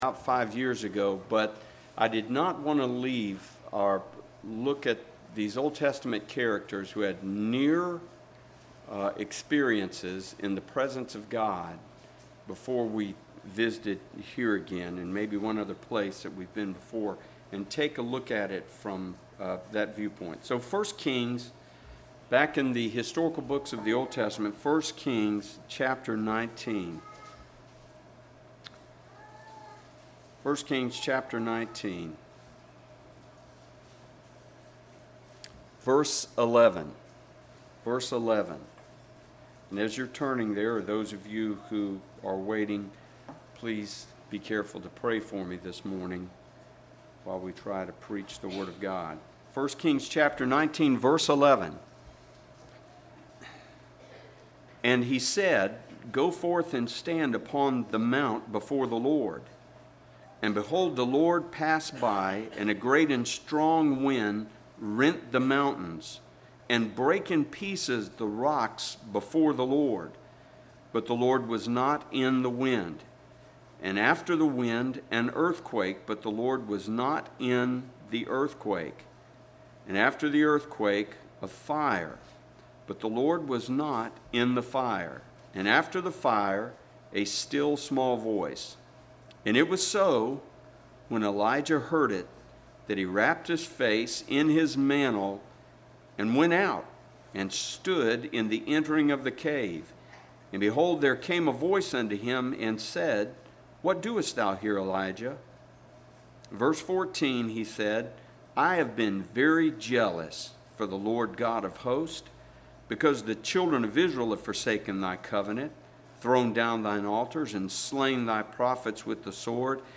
09/29/19 Sunday Morning